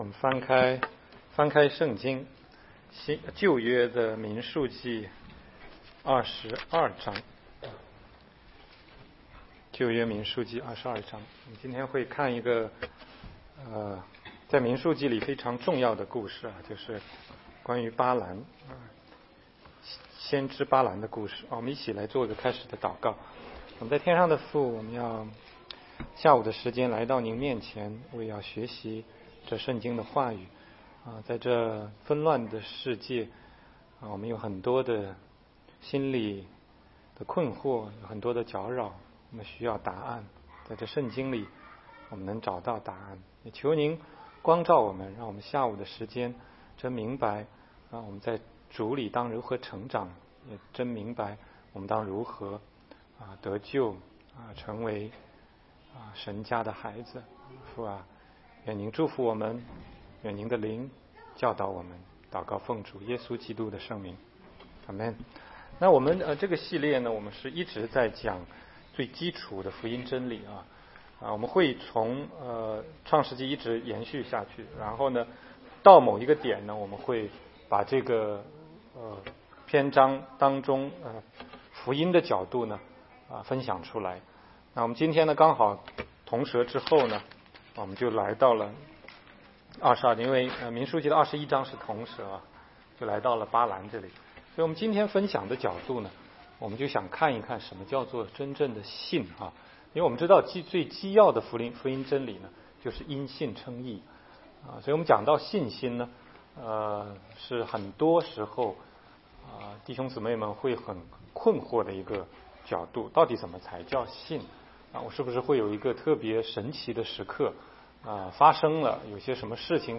16街讲道录音 - 巴兰的教训